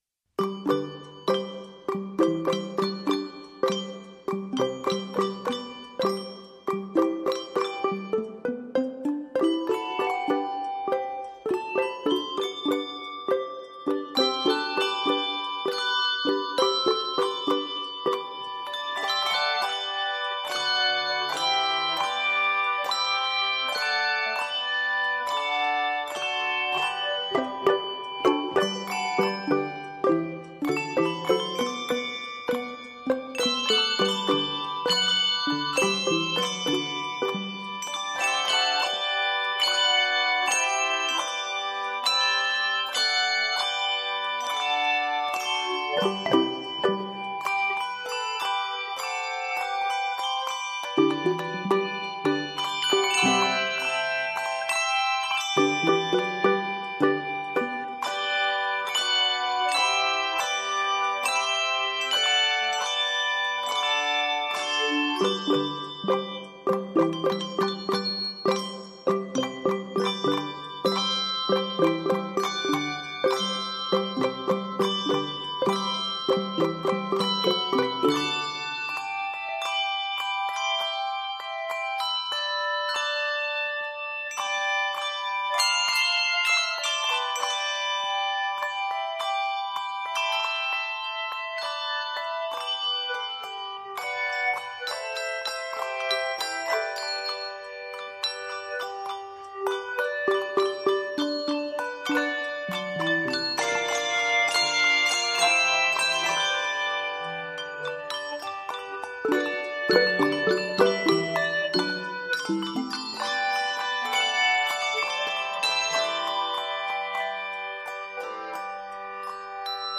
This rhythmically vibrant arrangement incorporates